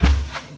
sounds / mob / cow / step2.ogg